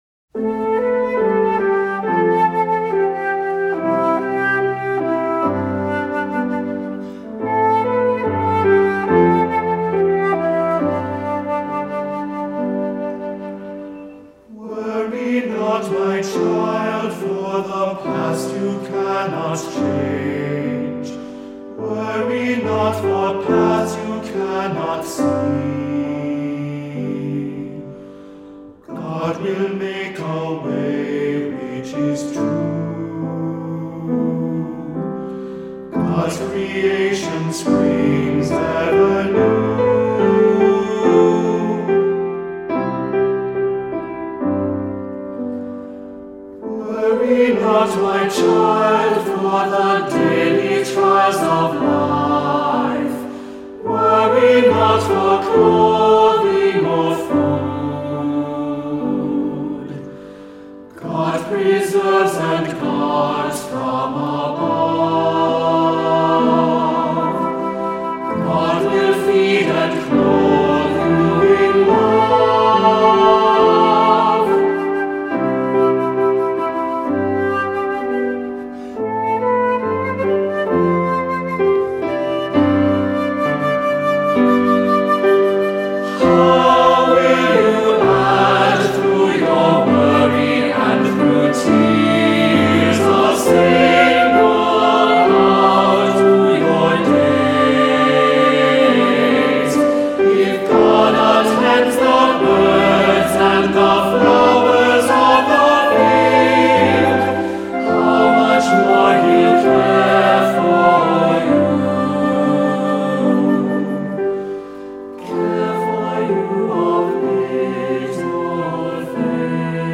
Voicing: Two-part equal